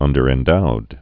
(ŭndər-ĕn-doud)